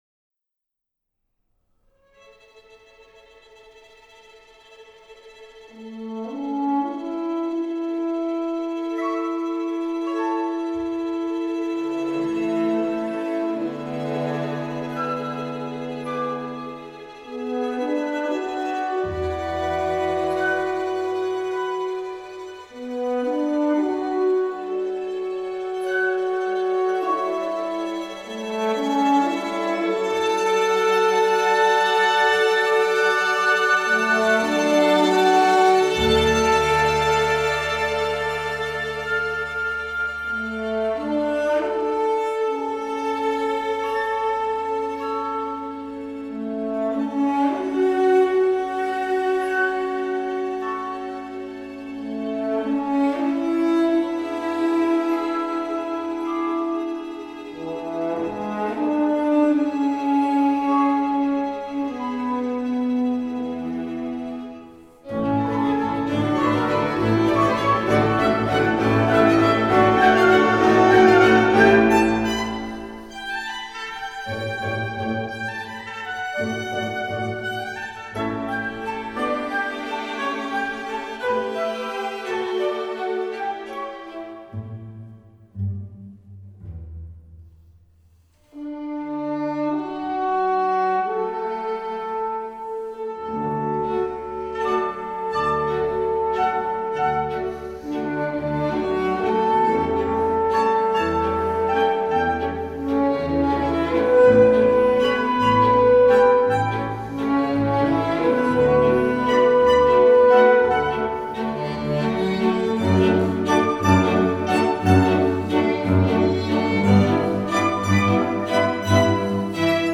by The Chamber Orchestra by waltz in vienna | The Original Viennese Waltz